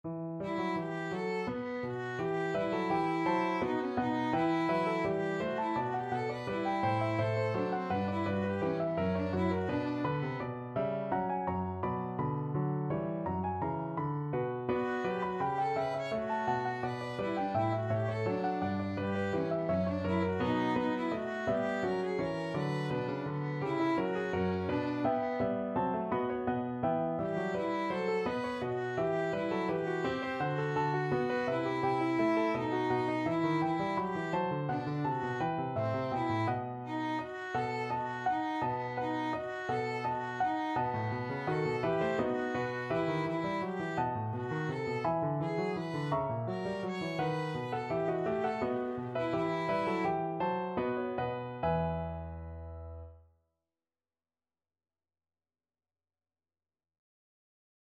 3/8 (View more 3/8 Music)
Classical (View more Classical Violin Music)